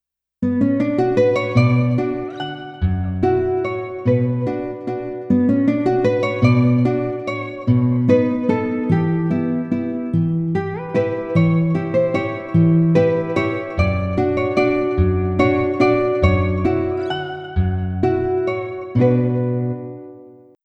着信メロディ
尚、着メロの雰囲気を醸すために原曲のピッチを2度近く上げており、長さは30秒程度としています。